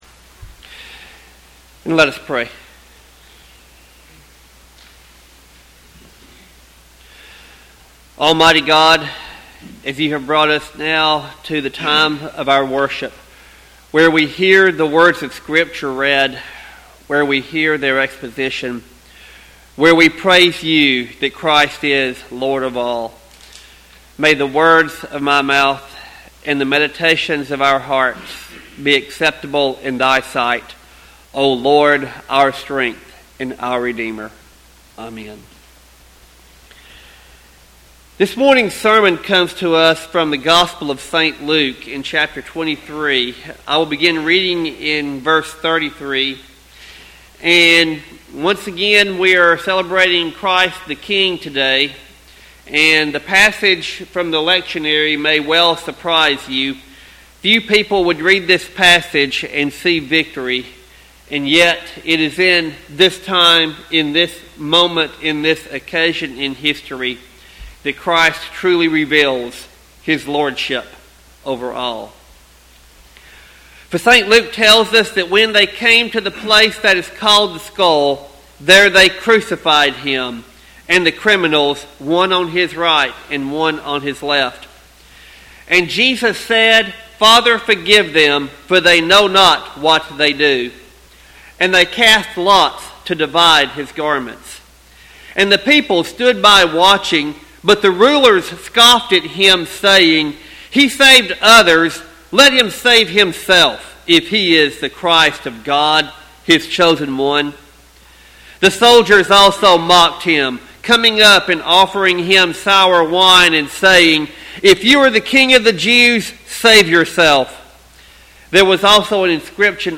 Sermon text: Luke 23:33-43.